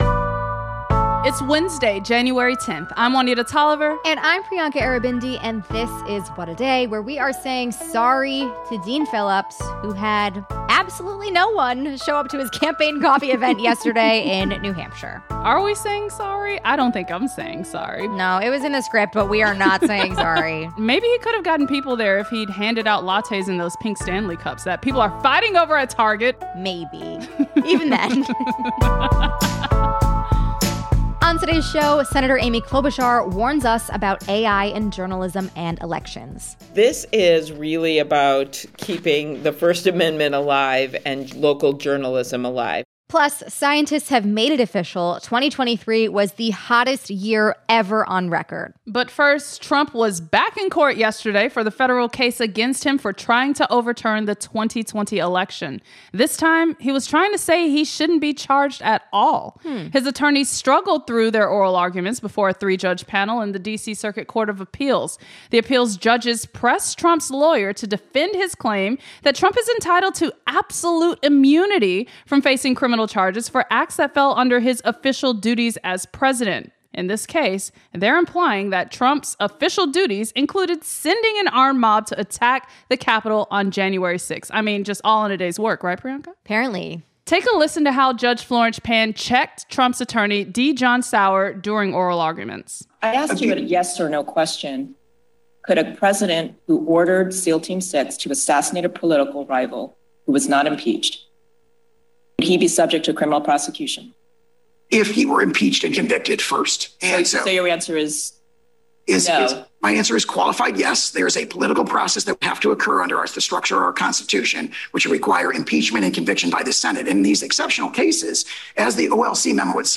We’re joined by the Senator to discuss that bill and the larger effort to crack down on A.I.